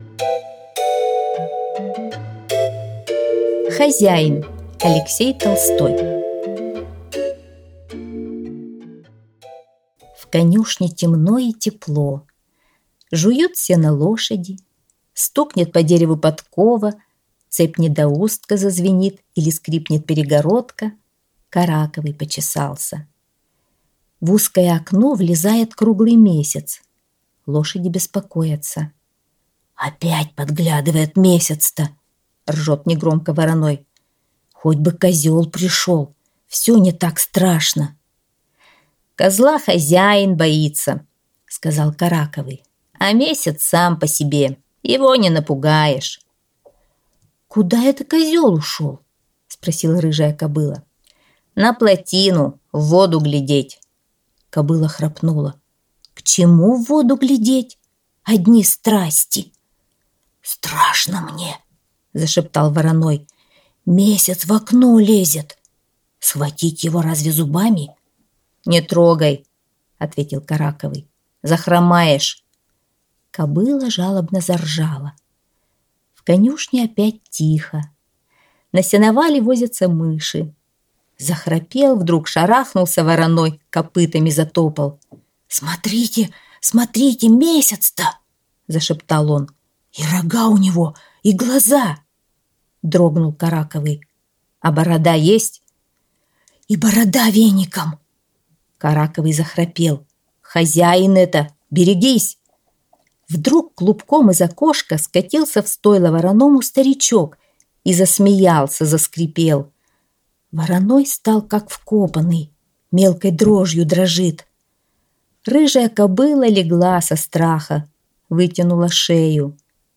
Хозяин – Толстой А.Н. (аудиоверсия)
Аудиокнига в разделах